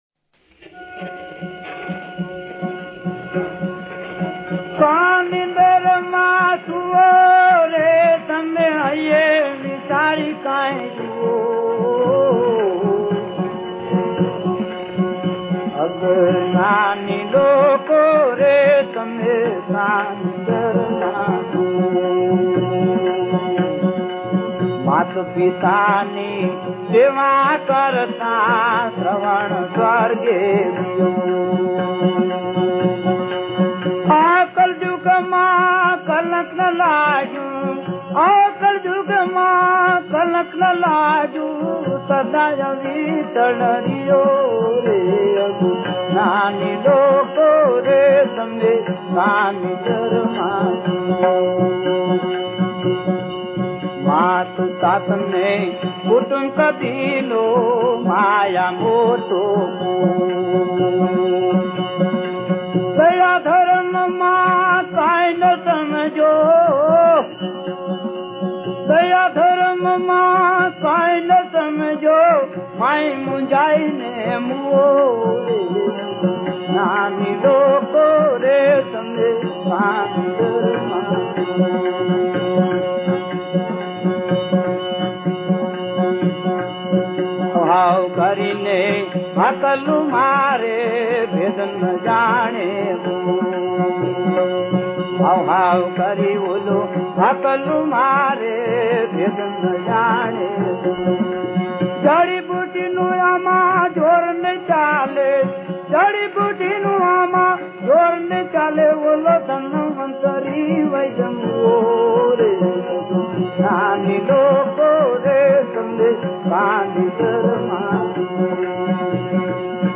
The best message and very good vocal of original bhajan